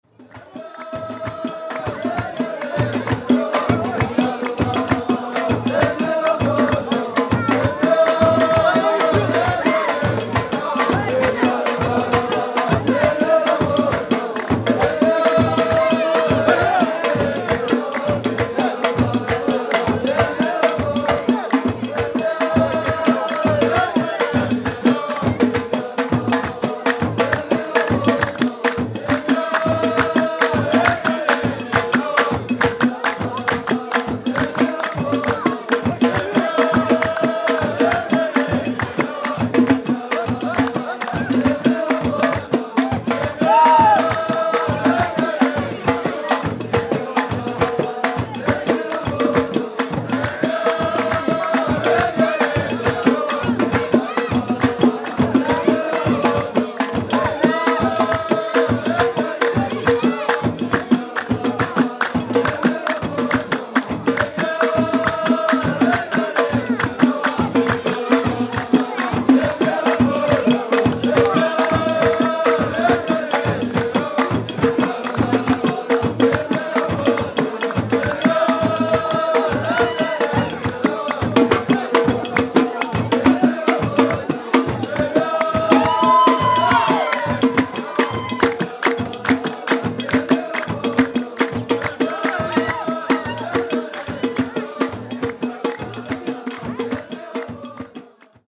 Wir waren während der Zeit des islamischen Opferfestes in Imlil. Sieben Tage lang konnte man jeden Abend die Trommeln und Gesänge der Berber hören.
In Imlil wird das Opferfest gefeiert mit Musik und Tanz
berbermusik-marokko.mp3